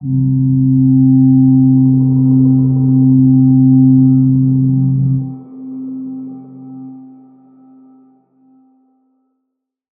G_Crystal-C4-mf.wav